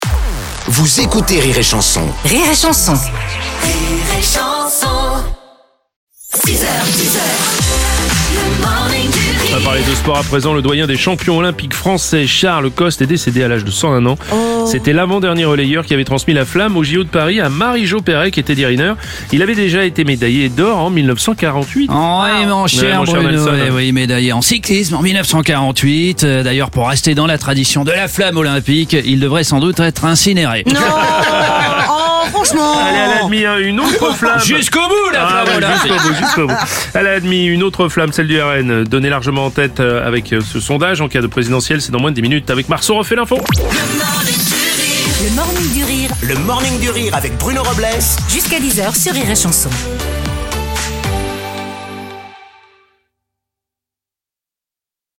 Comédie pour toute la famille Divertissement Rire et Chansons France Chansons France Tchat de Comédiens Comédie
débriefe l’actu en direct à 7h30, 8h30, et 9h30.